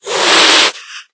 hiss2.ogg